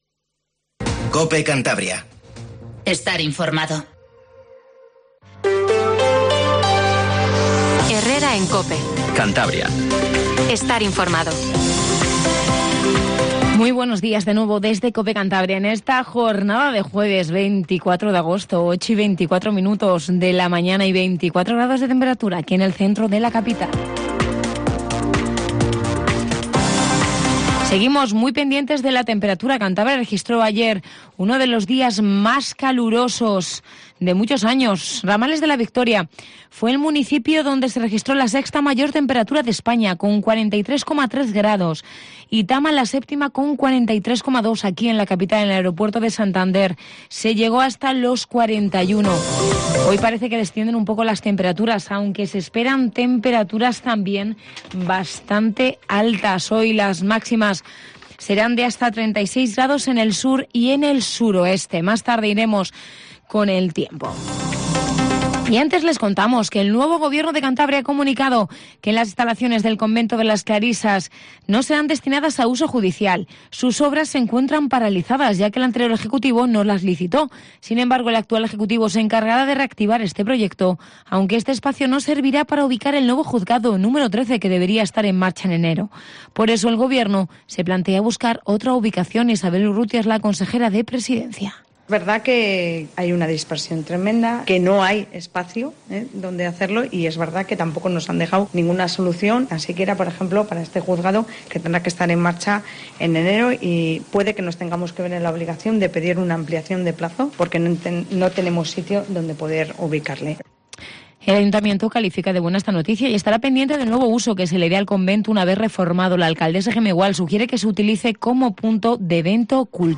Informativo Matinal Cope 08:20